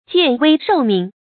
見危授命 注音： ㄐㄧㄢˋ ㄨㄟ ㄕㄡˋ ㄇㄧㄥˋ 讀音讀法： 意思解釋： 在危急關頭勇于獻出自己的生命。